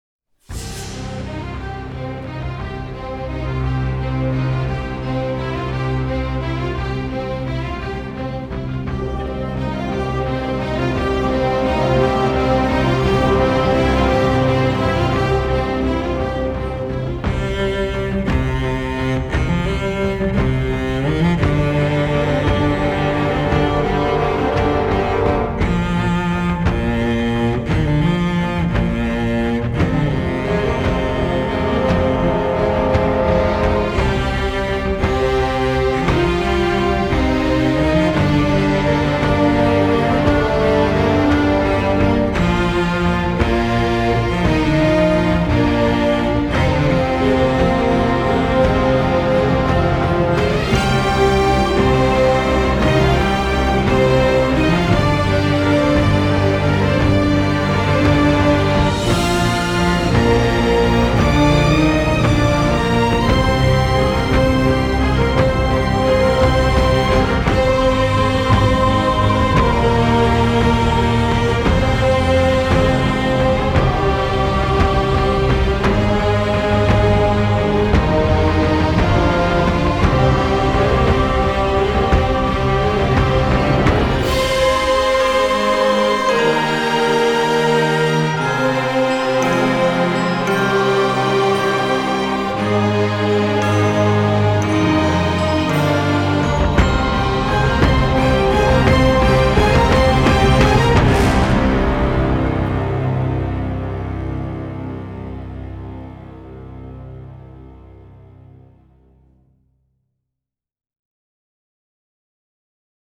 • Качество: 320, Stereo
без слов
инструментальные
оркестр
эпичные
Контрабас
Оркестровое исполнение